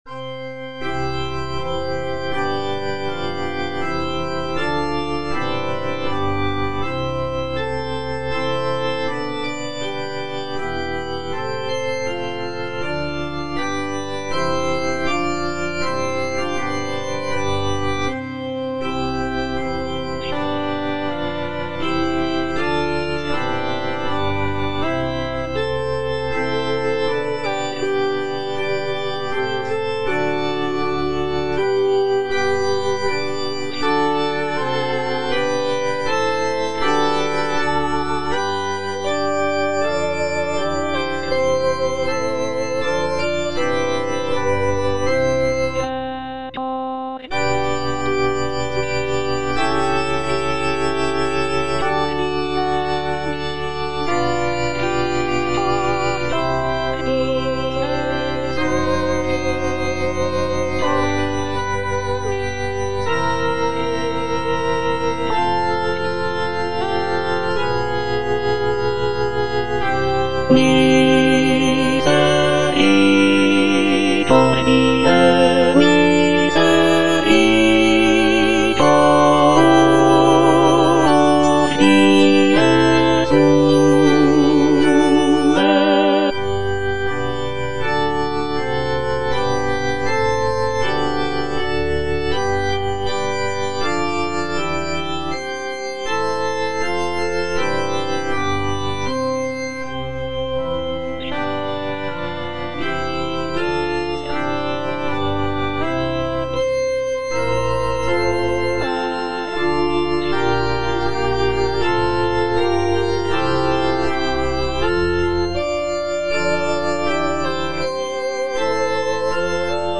B. GALUPPI - MAGNIFICAT Suscepit Israel - Tenor (Emphasised voice and other voices) Ads stop: auto-stop
"Magnificat" by Baldassare Galuppi is a sacred choral work based on the biblical text of the Virgin Mary's song of praise from the Gospel of Luke.
The work features intricate vocal lines, rich harmonies, and dynamic contrasts, creating a powerful and moving musical experience for both performers and listeners.